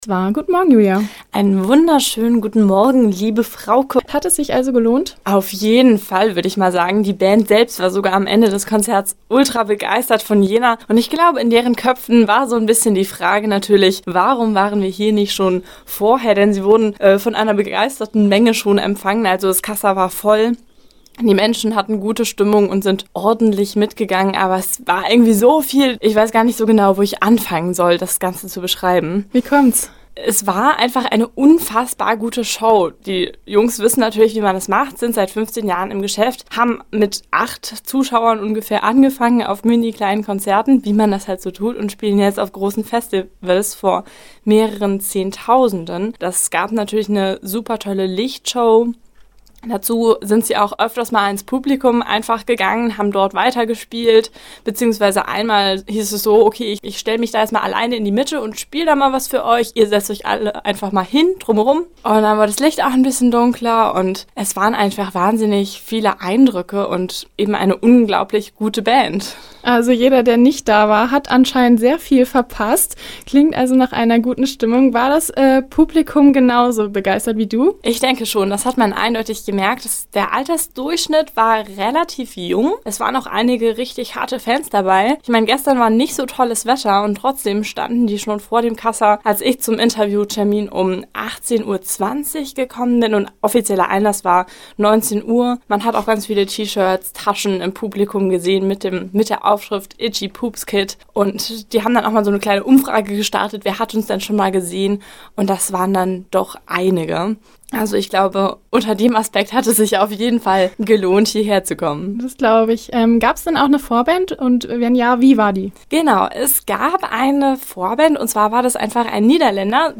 Konzertrezension: Itchy Poopzkid – Campusradio Jena